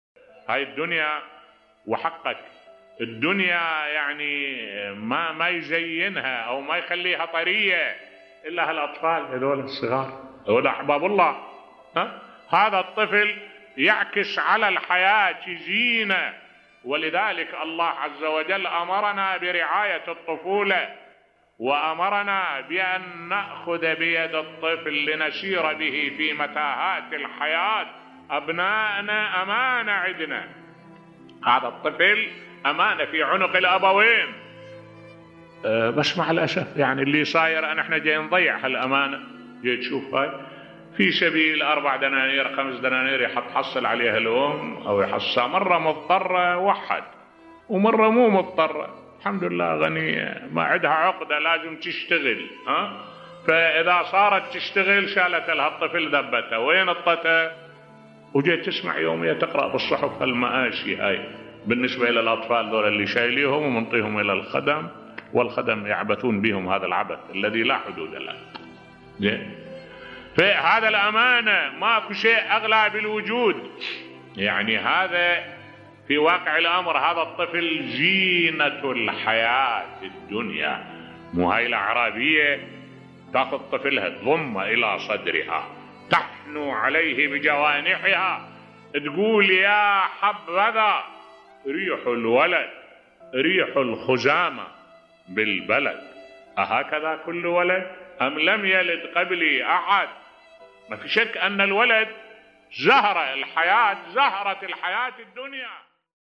ملف صوتی اجمل كلام عن الأطفال بصوت الشيخ الدكتور أحمد الوائلي